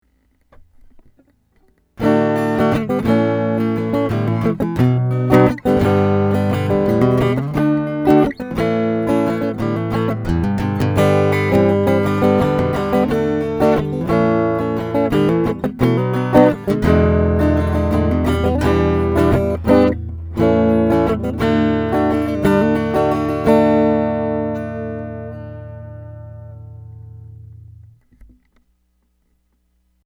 I put together a couple of quick audio clips to demonstrate the difference between going direct into my DAW vs going through the ProDI.
Guitar straight into the DAW
But even plugged in, there’s a detectable (at least to my ears) muffling of the tone, whereas with the ProDI, the guitar sounds richer.
I realize that with these recordings the differences are subtle at best.